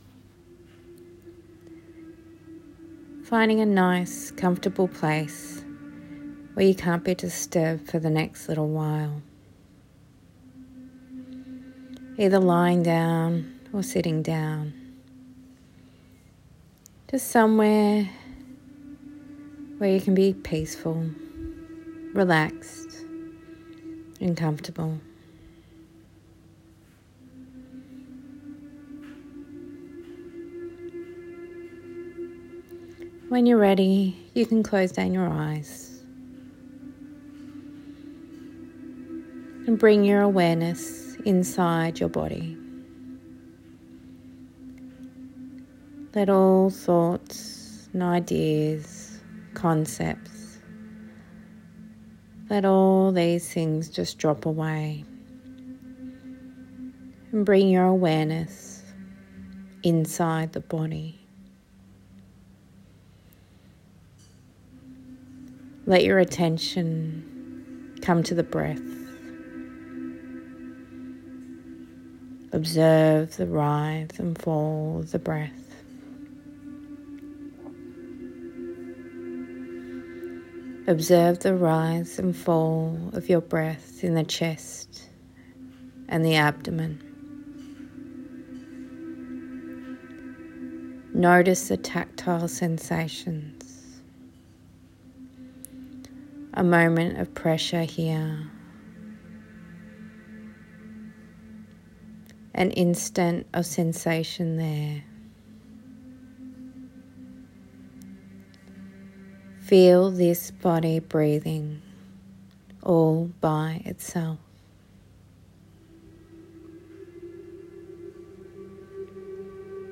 Mindfulness meditation • MINDFUL HYPNOTHERAPY AND WELLNESS